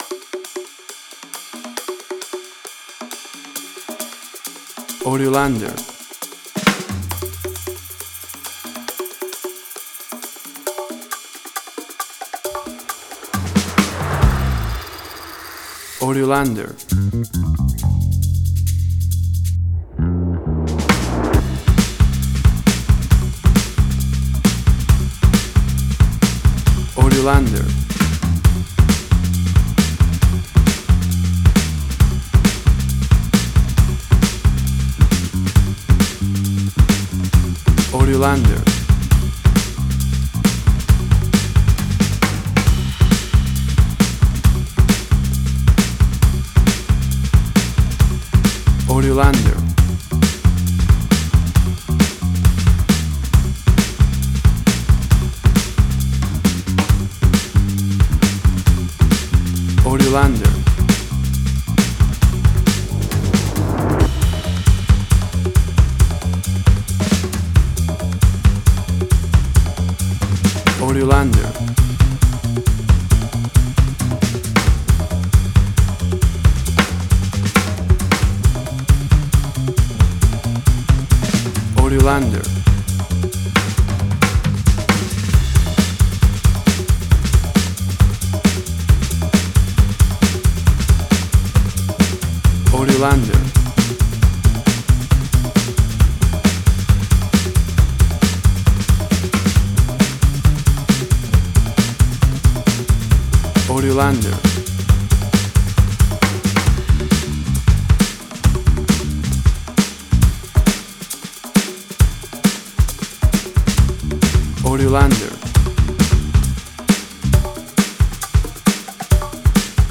WAV Sample Rate: 16-Bit stereo, 44.1 kHz
Tempo (BPM): 135